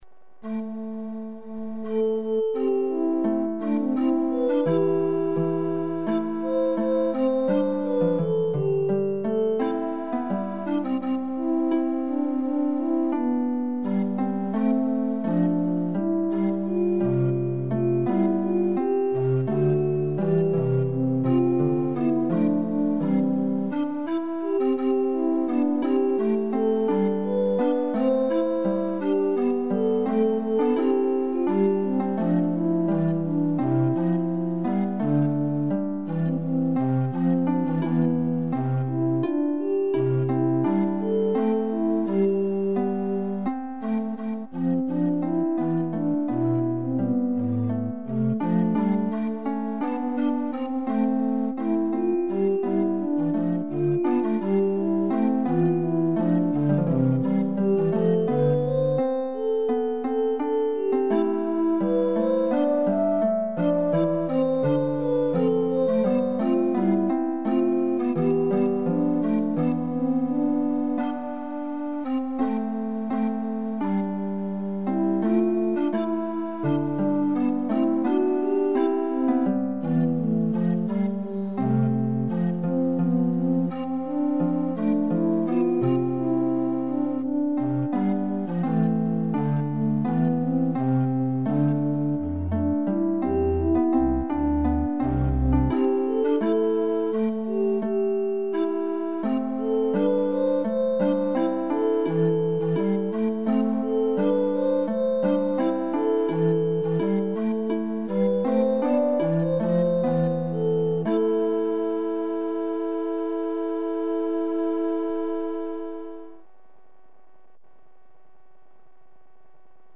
Chanson in neomedieval style, using some material from Ma bouche rit, by Johannes Ockeghem